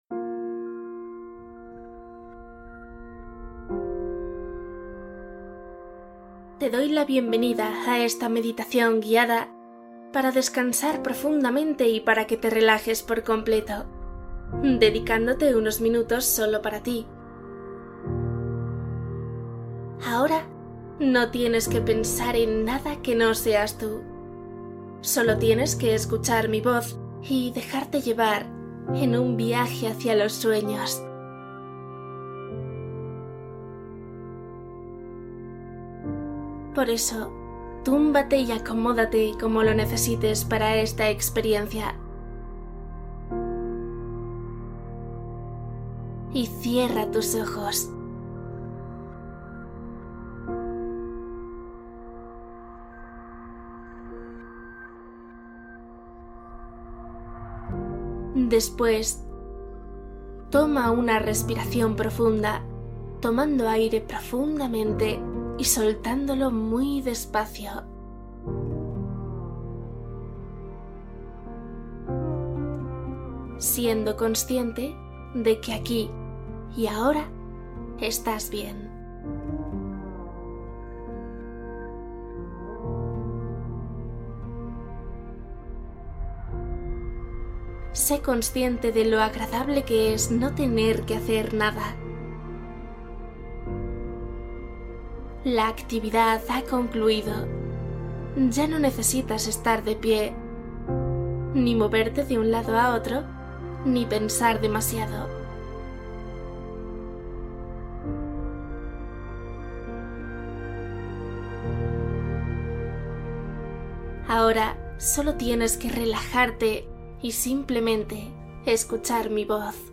Cuando el sueño se resiste: una meditación de profundidad